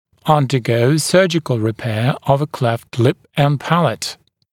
[ˌʌndə’gəu ‘sɜːʤɪkl rɪ’peə əv ə kleft lɪp ən ‘pælət][ˌандэ’гоу ‘сё:джикл ри’пэа ов э клэфт лип эн ‘пэлэт]перенести хирургическую пластику по поводу расщелины губы и нёба